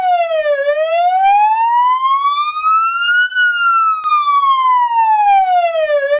Superiority In Quality Of The Non-speech Signals.
siren.wav